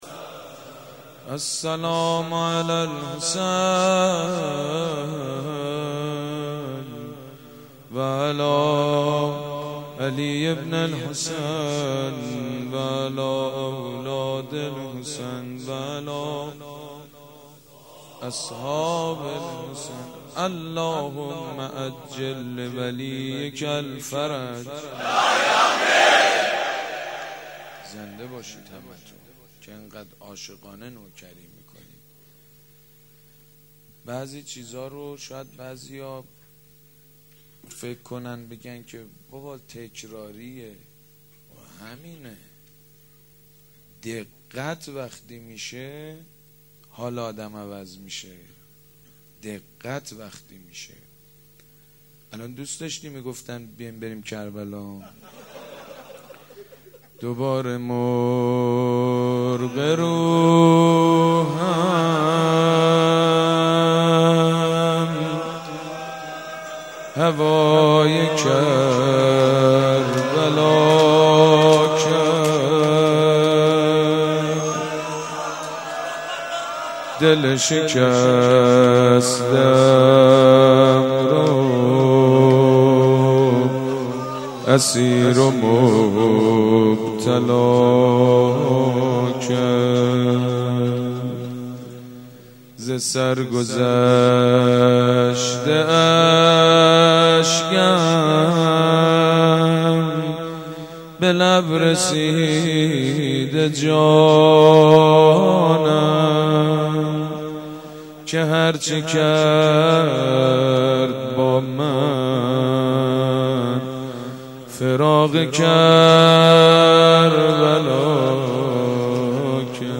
حاج سید مجید بنی فاطمه | روضه - دوباره مرغ روحم .:اشکال در بارگذاری پخش کننده:.
سید مجید بنی فاطمه- شب ششم محرم 1437- ریحانه الحسین (1).mp3